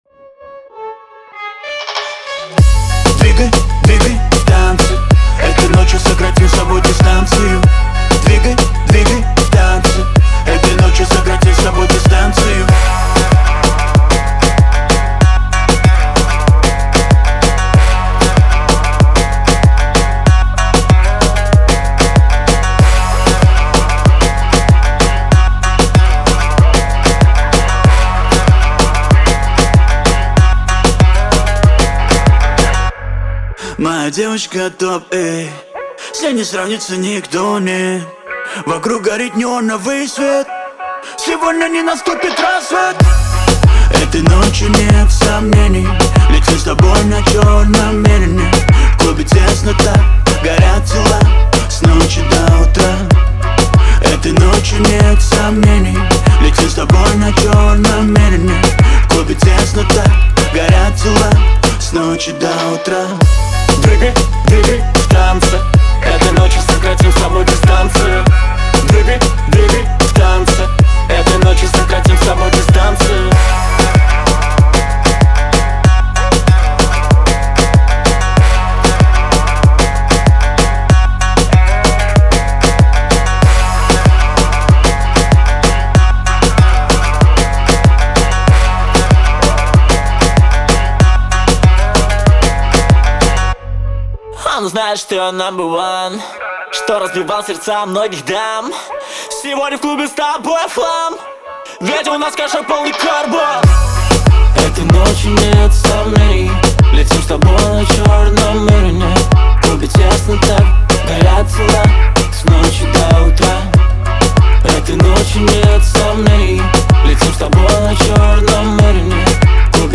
это энергичный трек в жанре хип-хоп